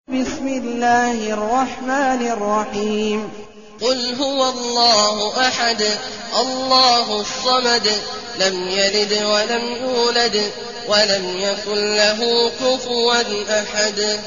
المكان: المسجد الحرام الشيخ: عبد الله عواد الجهني عبد الله عواد الجهني الإخلاص